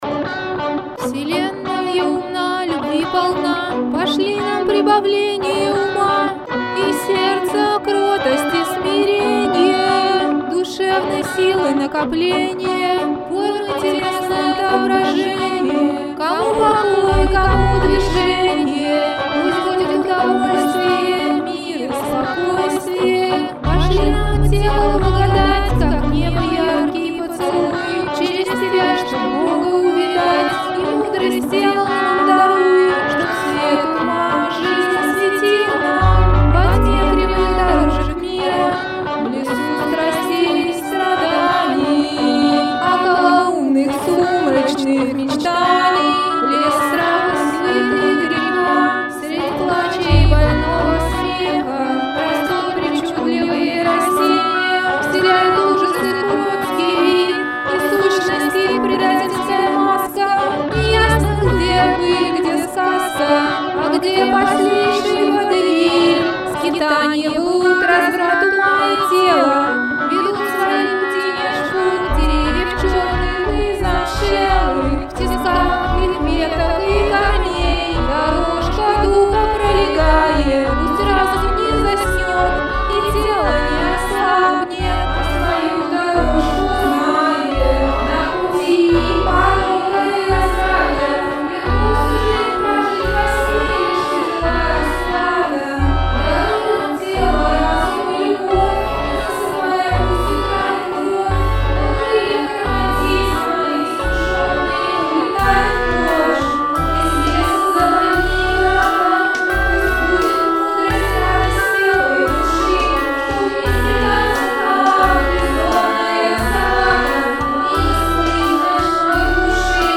Музыкальный медитативный аудио альбом